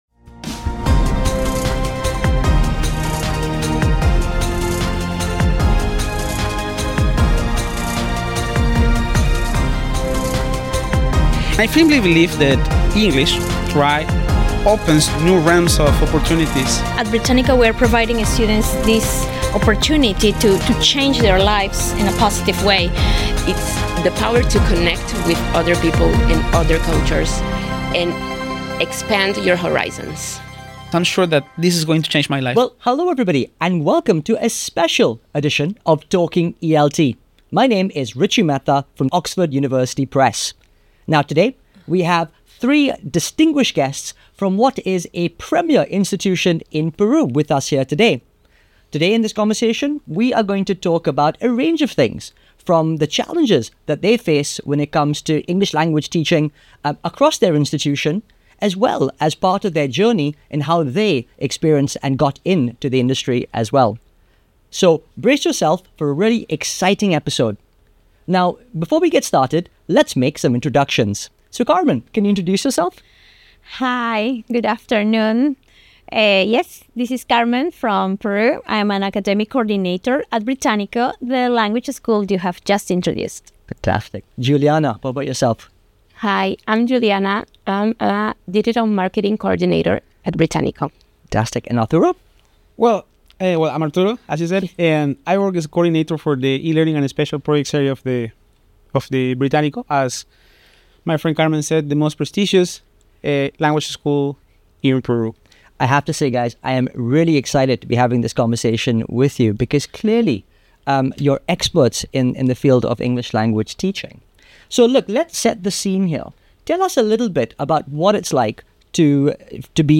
We invited a marketer, an e-Learning specialist and an academic coordinator to discuss topics and challenges relevant to their specific teaching and learning context in Peru.